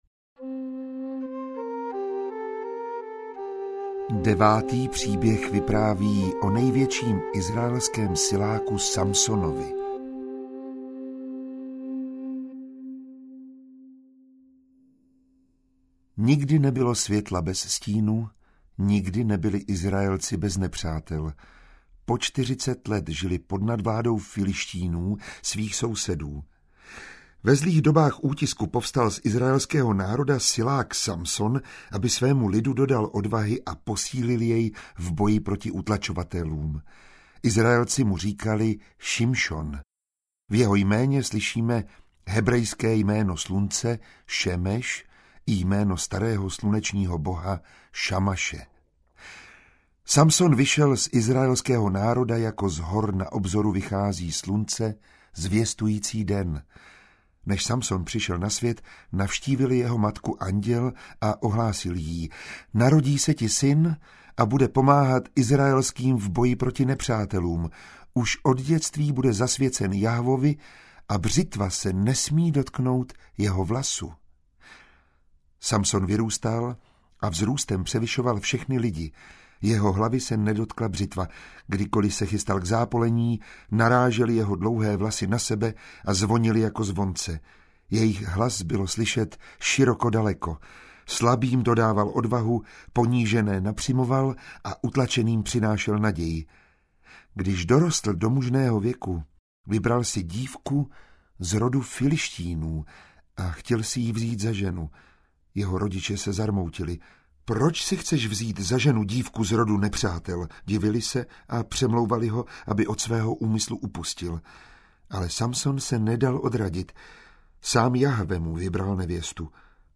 Příběhy starého Izraele audiokniha
Ukázka z knihy
• InterpretMiroslav Táborský